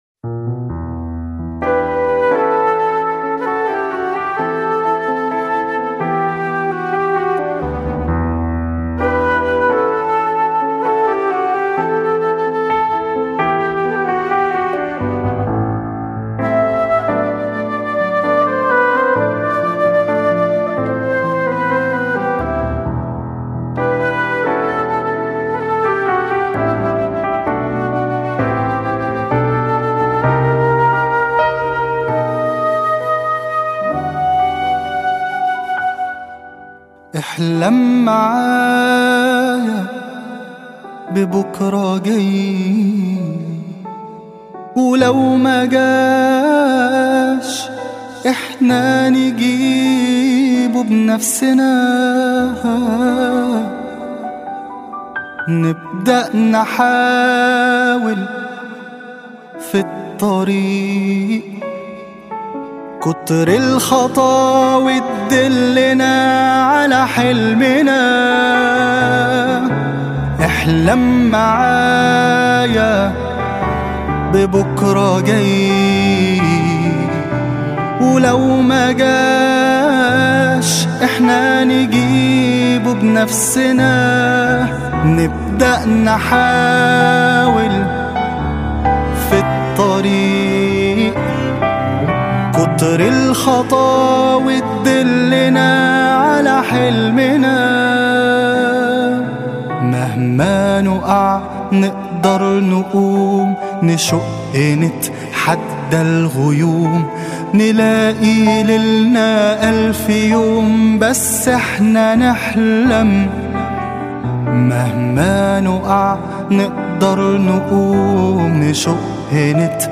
音乐类型：Arabic Pop